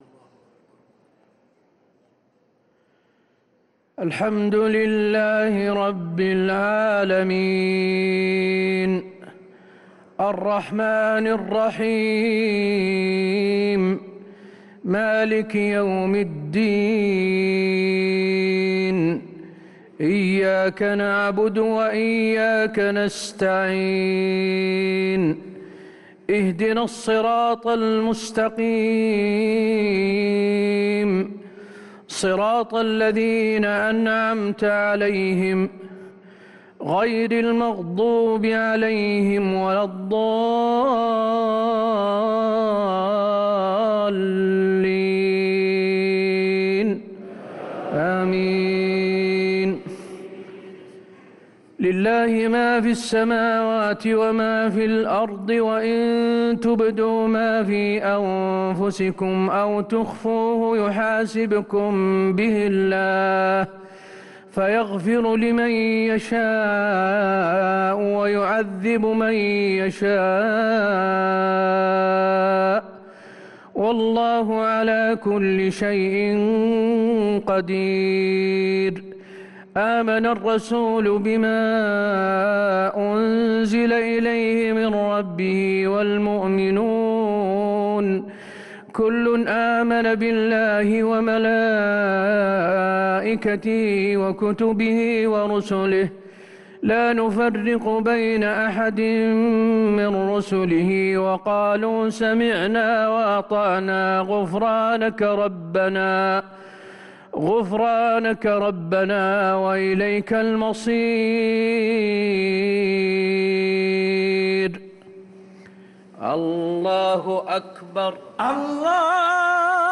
صلاة العشاء للقارئ حسين آل الشيخ 13 رمضان 1444 هـ
تِلَاوَات الْحَرَمَيْن .